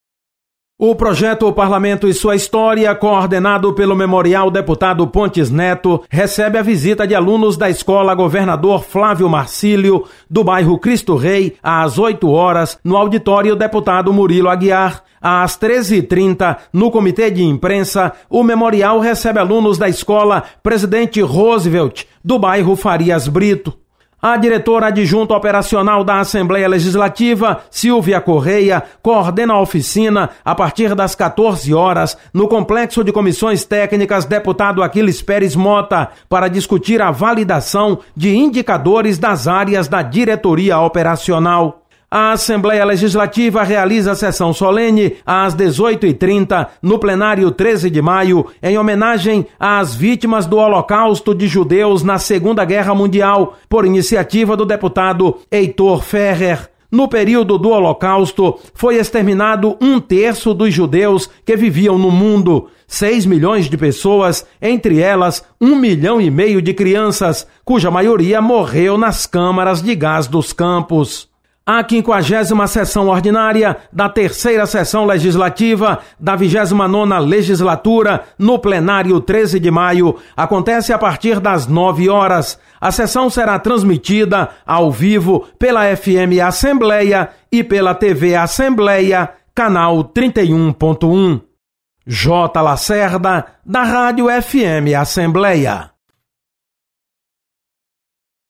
Acompanhe as atividades de hoje da Assembleia Legislativa. Repórter